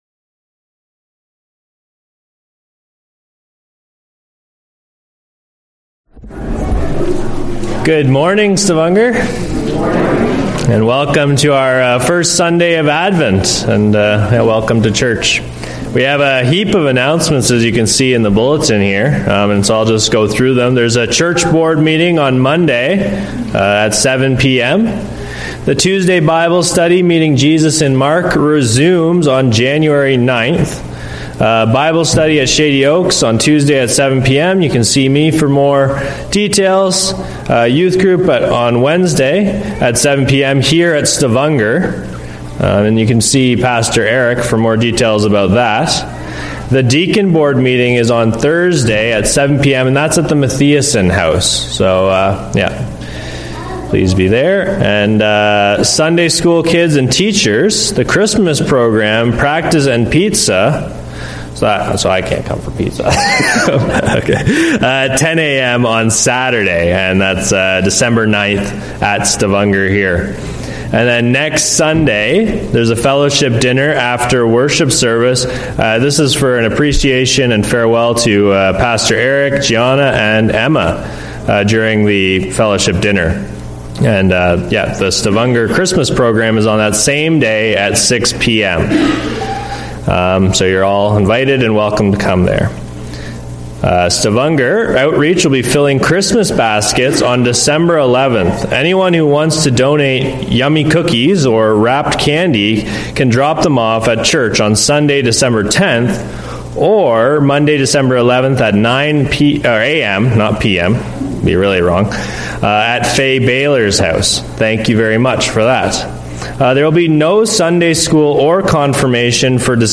Sermons - Stavanger Lutheran Church
From Series: "Sunday Worship"